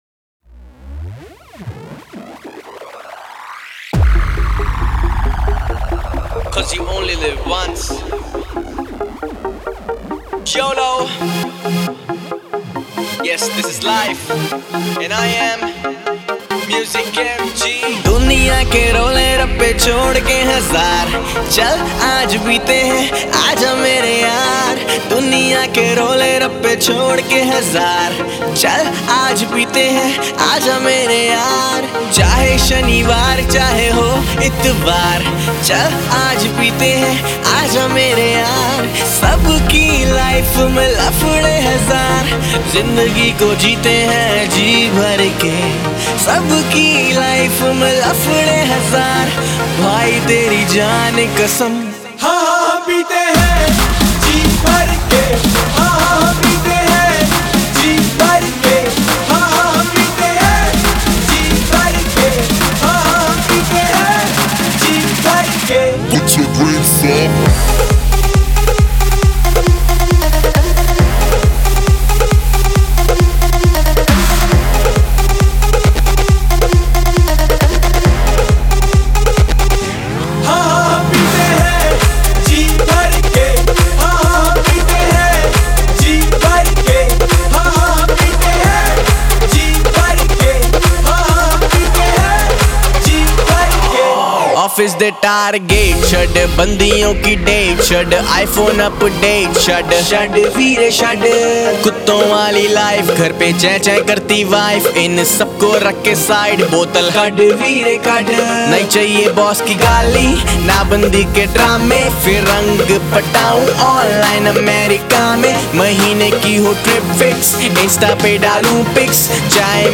Punjabi Bhangra MP3 Songs